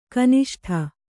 ♪ kaniṣṭha